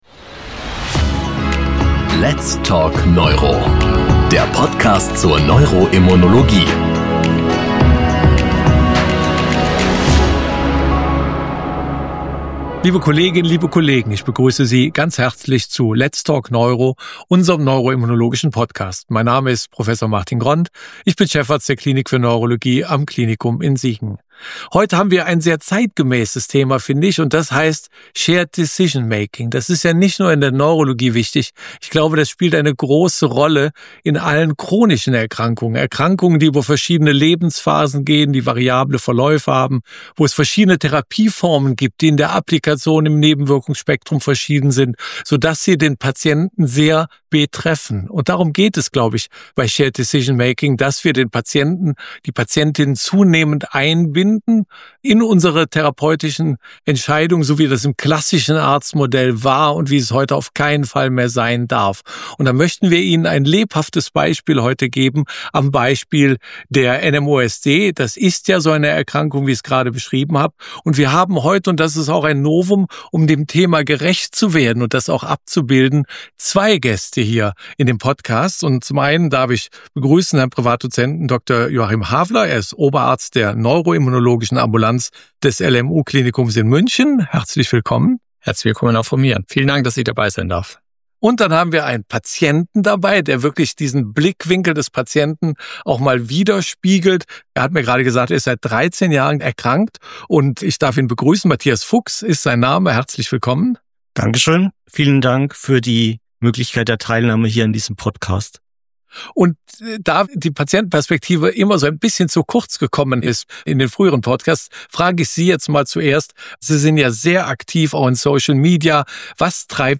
Moderator gemeinsam mit zwei Experten